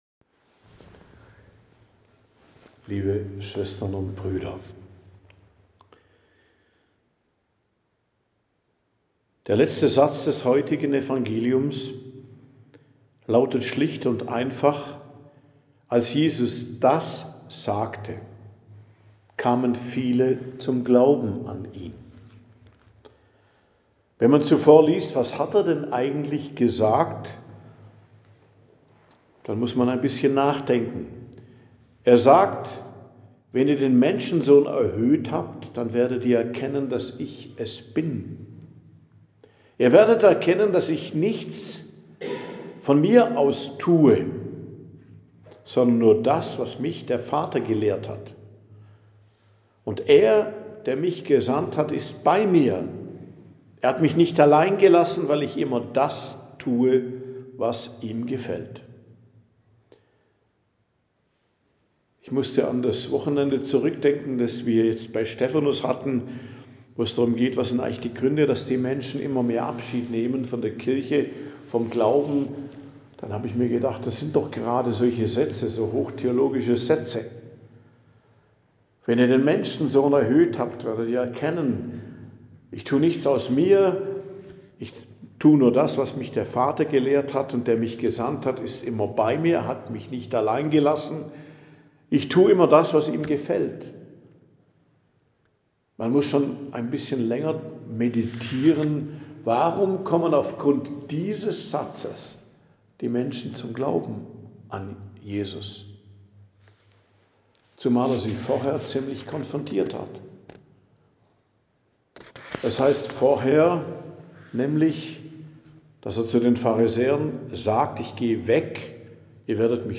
Predigt am Dienstag der 5. Woche der Fastenzeit, 24.03.2026